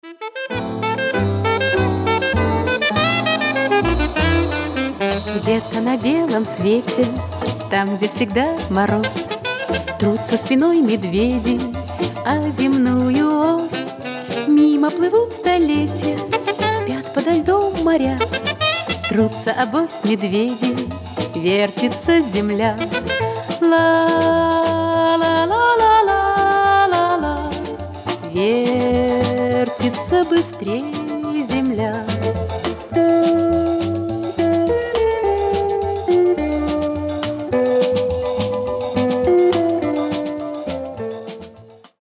• Качество: 320, Stereo
из фильмов
эстрадные
60-е
советское кино